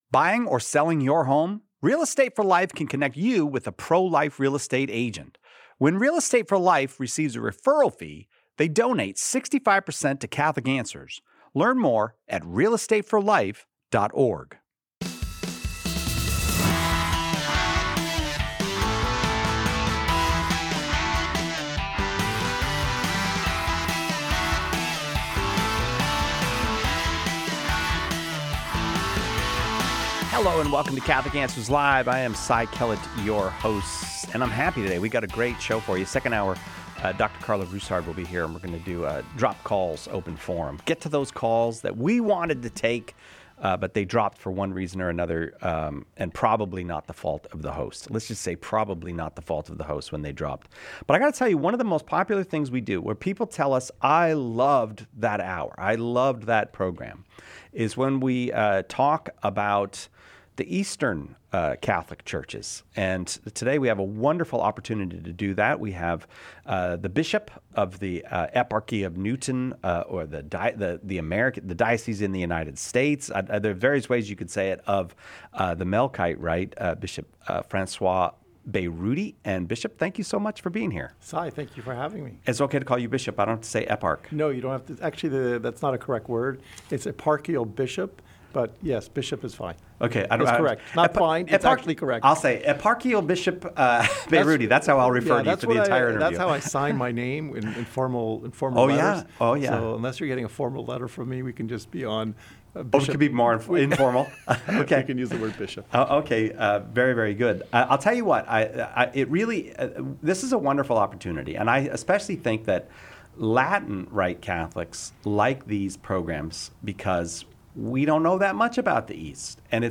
What Is The Melkite Catholic Church? - An Interview With Bishop François Beyrouti